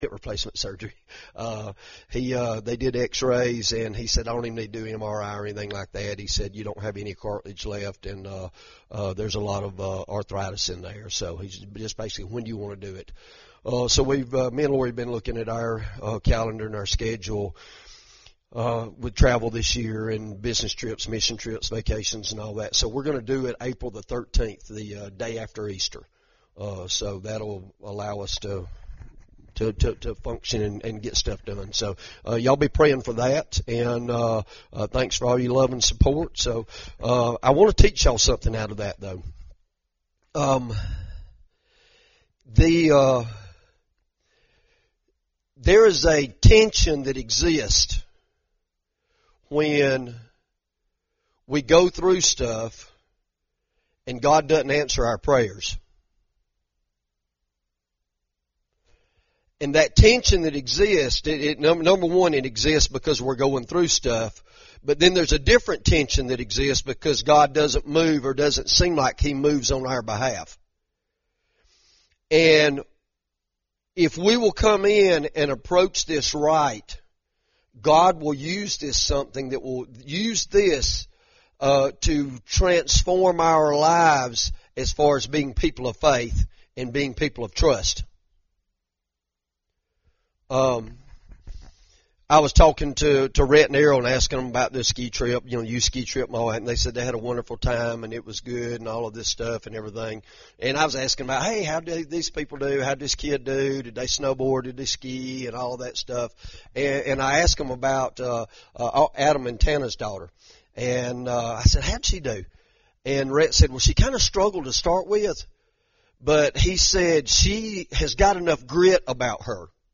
The Sunday 8/30/2020 Worship and Message